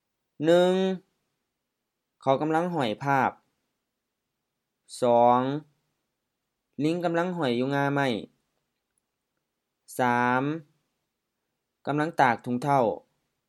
เขา khao M เขา personal pronoun: he, she
ลิง liŋ HR ลิง monkey
ถุงเท้า thuŋ-thao M-HF ถุงเท้า sock